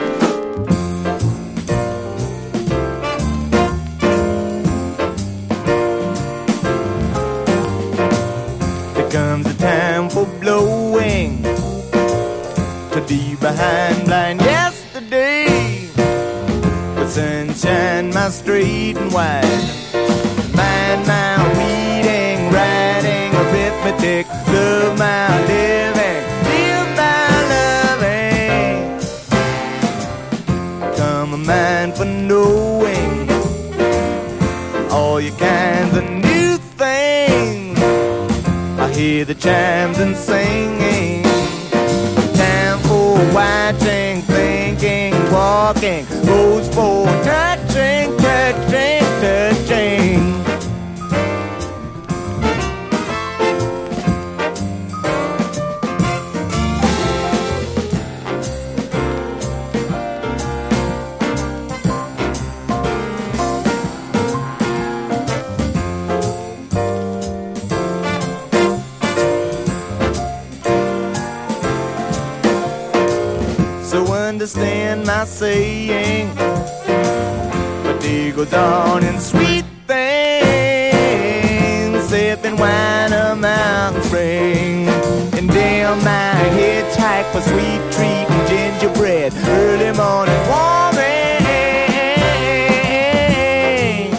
陽だまりメロウ・フォークS.S.W.必携盤！